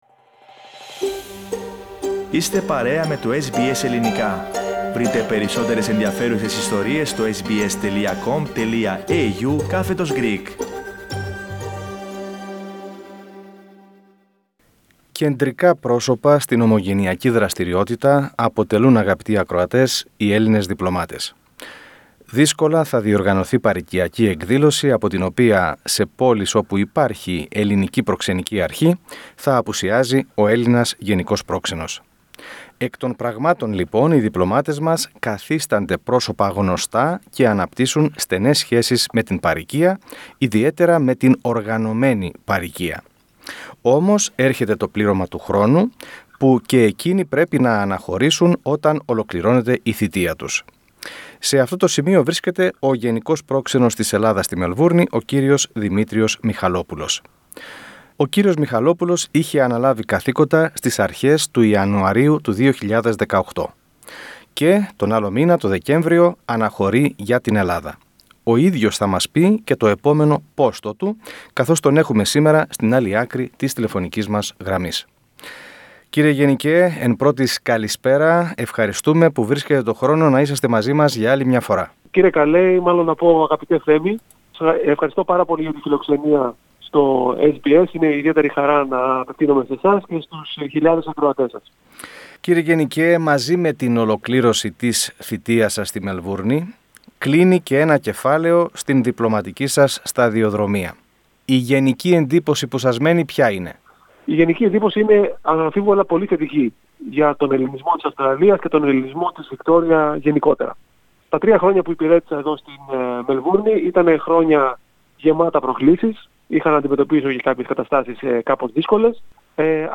Ενόψει της αναχώρησής του για Αθήνα για να υπηρετήσει στο νέο του πόστο στο Υπουργείο Εξωτερικών, ο κ. Μιχαλόπουλος έδωσε έναν εφ’ όλης της ύλης απολογισμό της θητείας του σε αποκλειστική συνέντευξη στο SBS Greek .